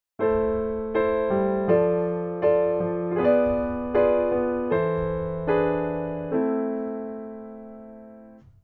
VIIo7 of Harmonic Minor Key